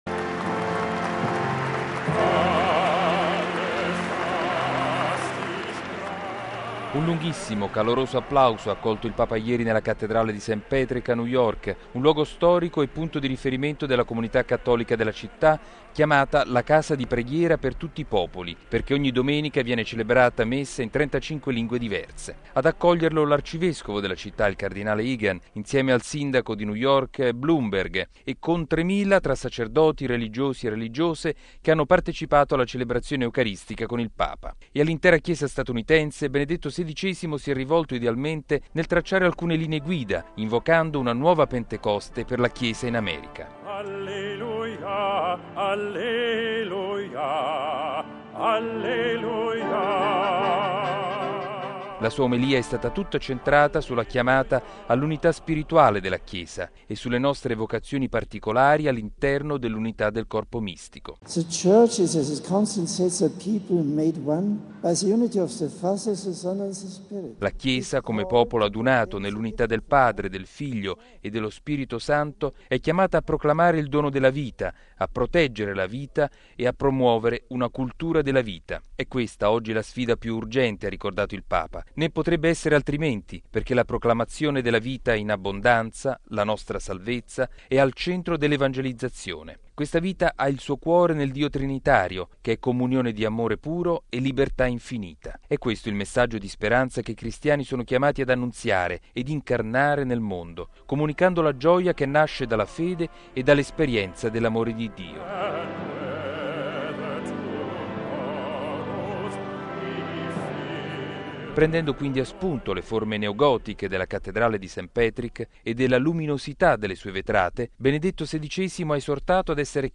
Sulla Messa nella Cattedrale di St. Patrick, il servizio del nostro inviato negli Stati Uniti
Un lunghissimo, caloroso applauso ha accolto il Papa ieri nella cattedrale di St. Patrick a New York, un luogo storico e punto di riferimento della comunità cattolica della città, chiamata la “casa di preghiera per tutti i popoli”, perchè ogni domenica viene celebrata Messa in trentacinque lingue diverse.